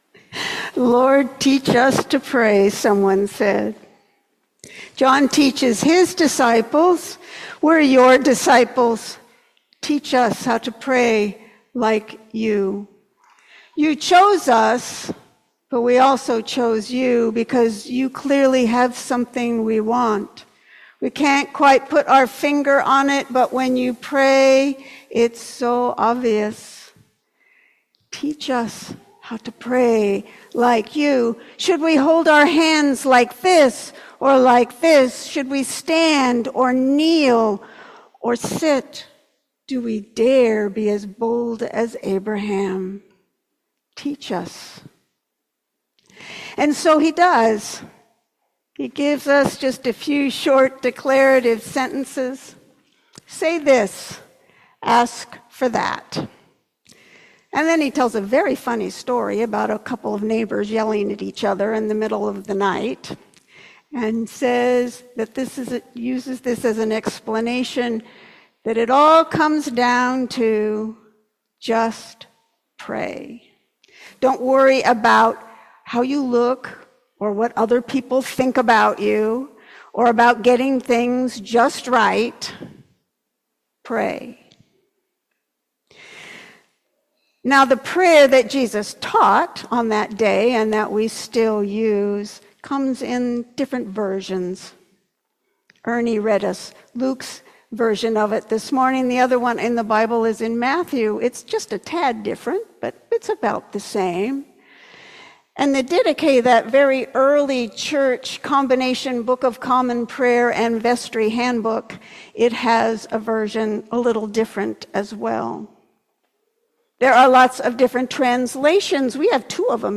Sermon on July 27